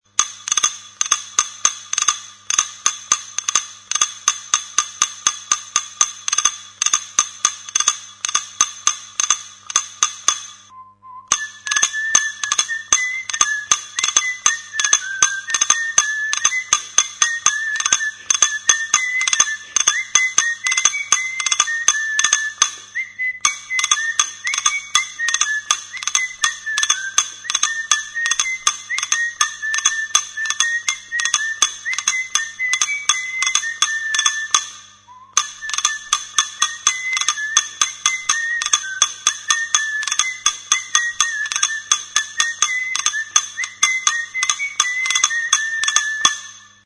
Idiófonos -> Golpeados -> Indirectamente
Koko azal erdia da. Mutur batean errebajatua dago eta soka batekin tinkatutako tablatxo bat du.